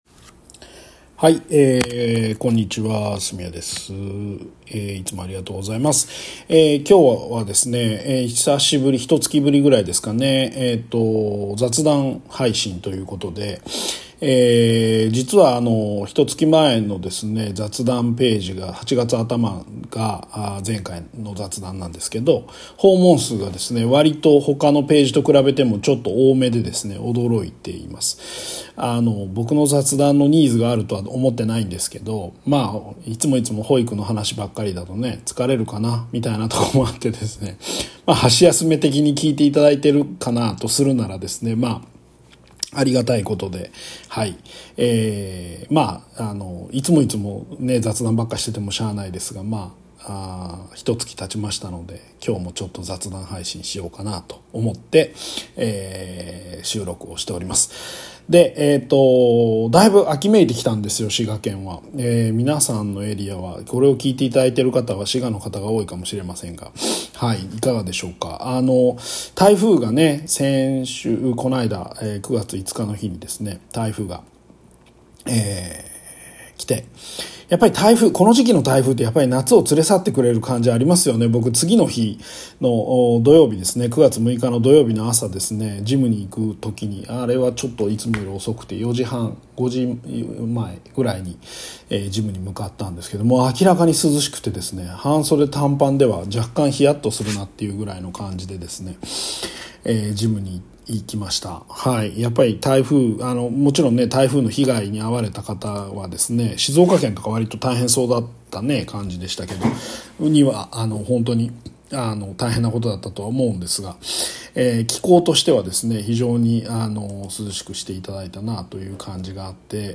１か月ぶりの雑談配信です。 この夏、楽しかった出来事とか、 嬉しかったことなどを話しています。